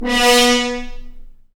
Index of /90_sSampleCDs/Roland L-CDX-03 Disk 2/BRS_F.Horns FX+/BRS_FHns Mutes